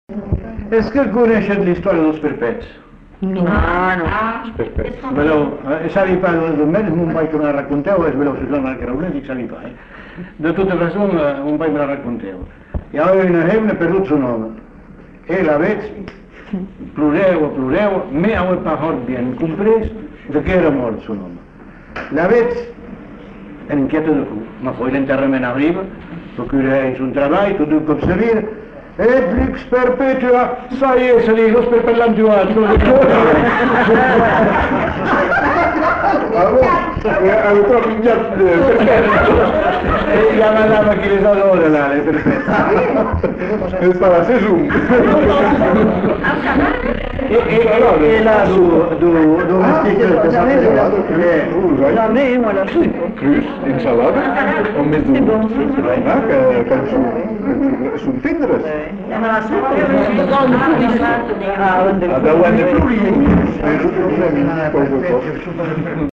Lieu : Uzeste
Genre : conte-légende-récit
Type de voix : voix d'homme
Production du son : parlé